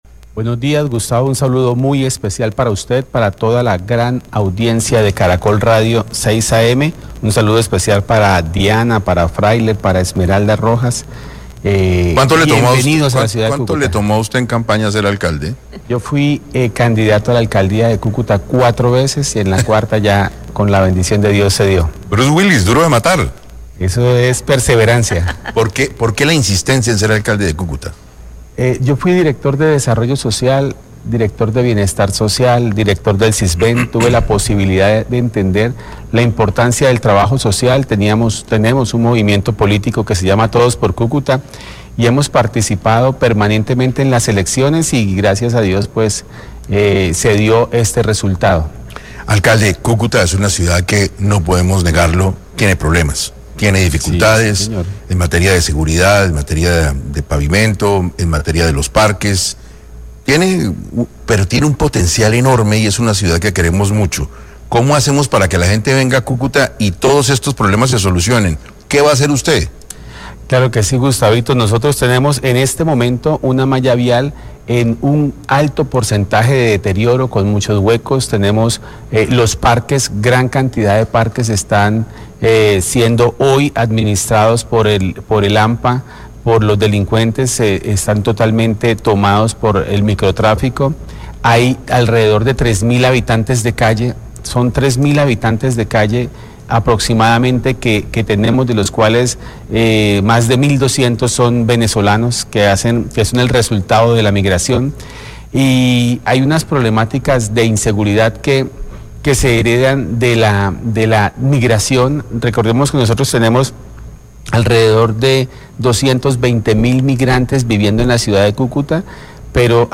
El alcalde de Cúcuta habló con el equipo de 6AM Hoy por Hoy sobre la seguridad en esta ciudad y las medidas a tomar.